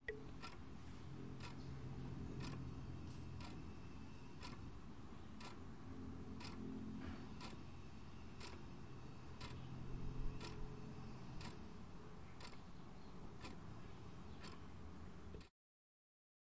时钟滴答作响
描述：录制的时钟滴答声。使用TASCAM DR40便携式录像机以48kHz 24bit（单声道）录制。
Tag: 抽动 滴答滴答 滴答 时间流逝 手表 时钟 小时 OWI 手表 挂钟